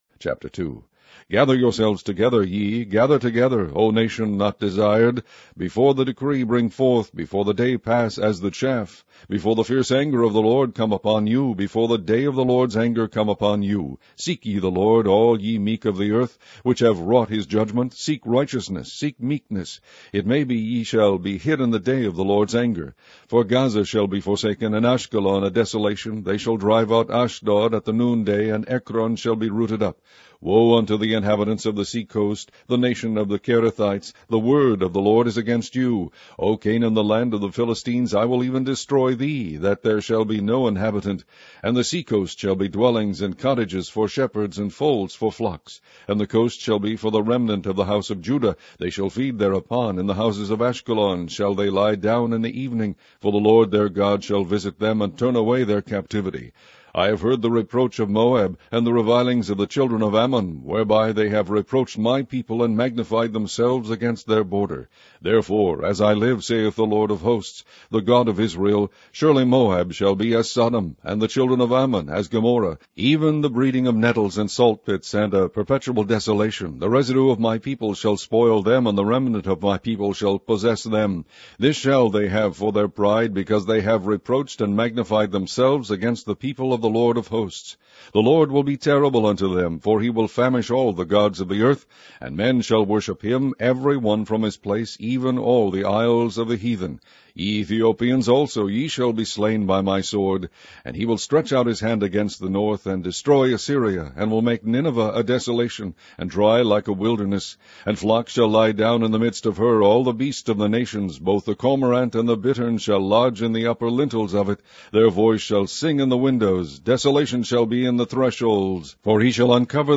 Primitive Baptist Digital Library - Online Audio Bible - King James Version - Zephaniah